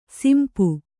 ♪ simpu